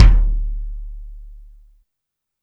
INSKICK16 -L.wav